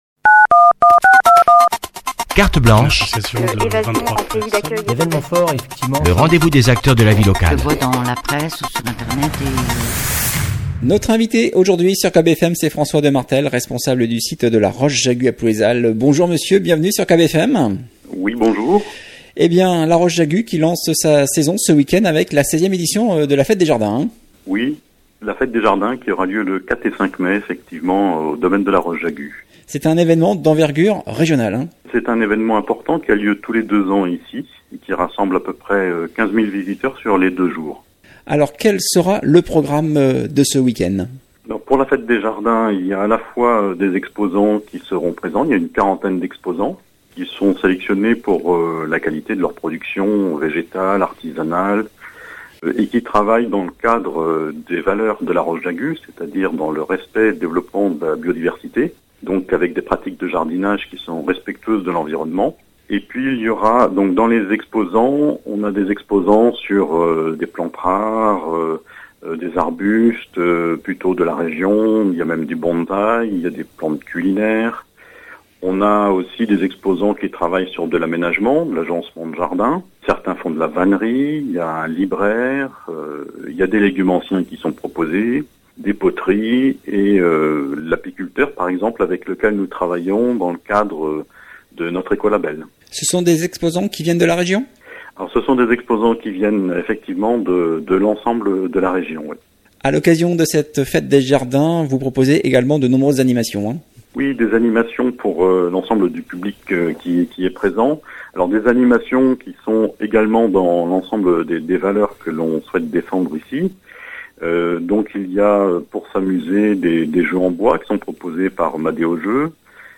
Invité de la rédaction ce vendredi